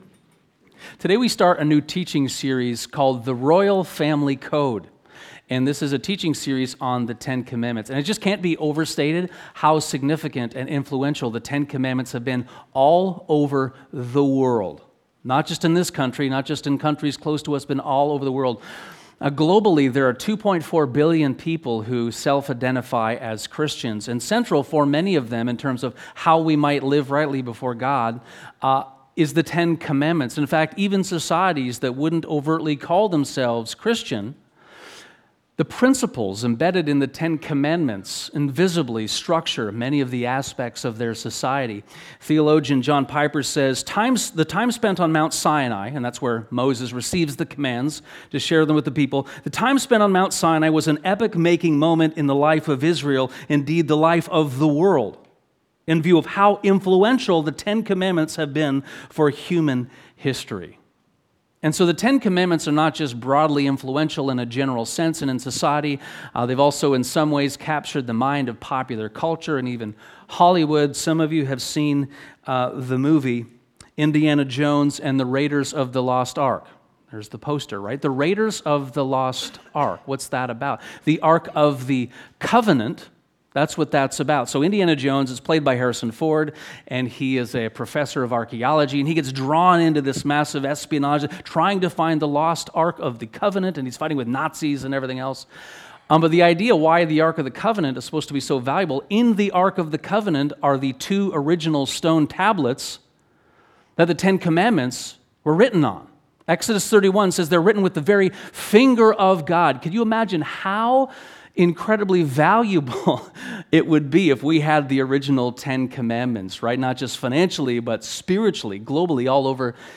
From Slavery to Royalty (10 Commandments, Part 1) (Sermon)